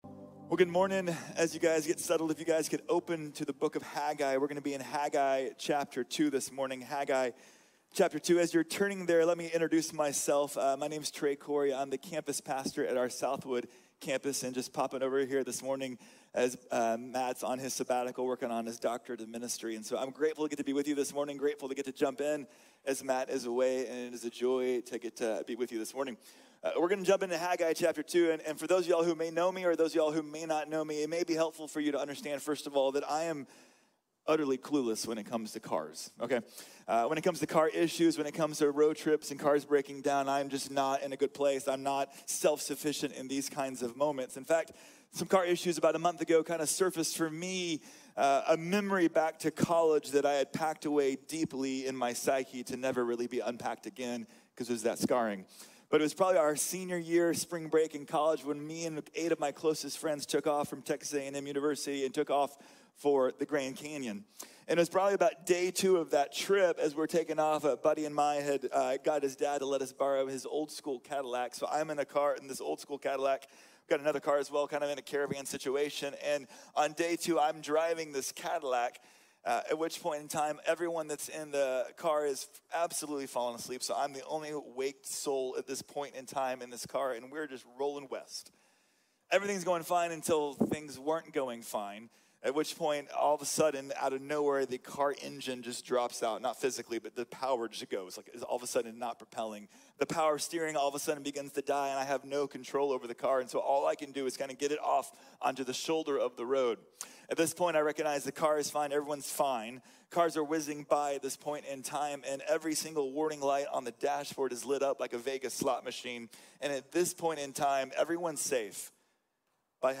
Haggai | Sermon | Grace Bible Church